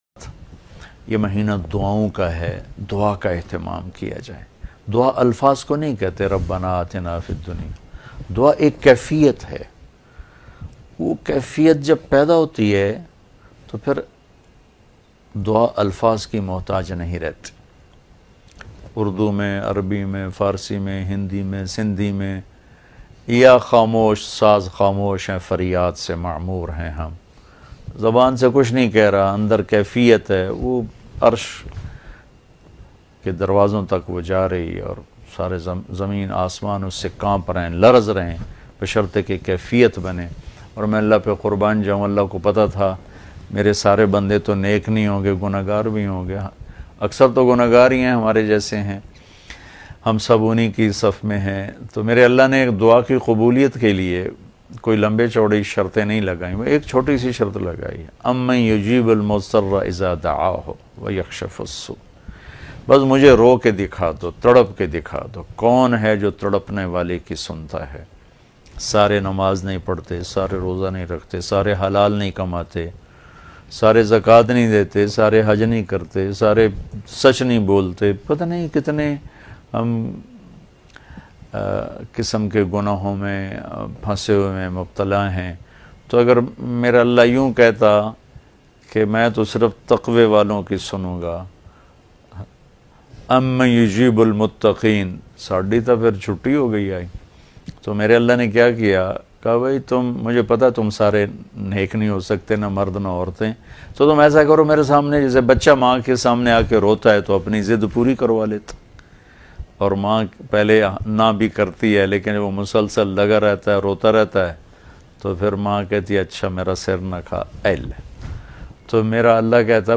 7 aisay time jab dua qabool hoti hai bayan mp3